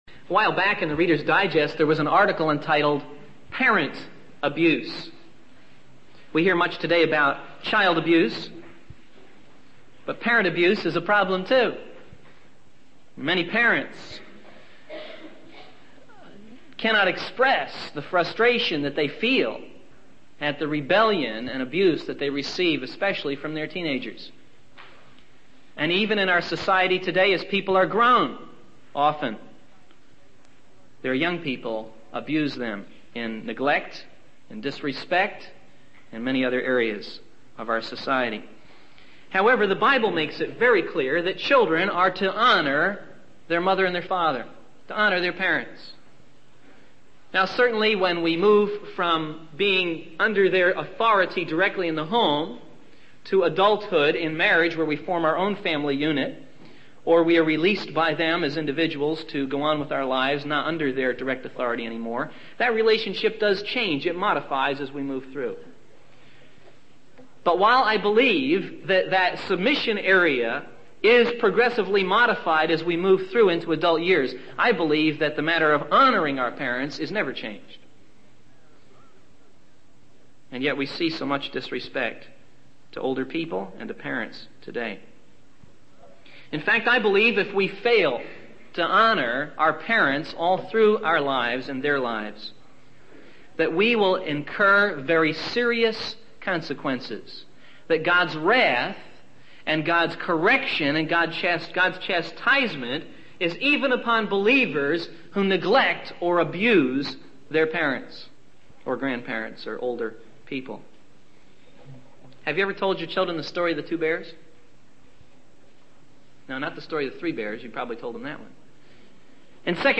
The sermon emphasizes the need for security and the potential challenges that can arise even when things seem to be going well.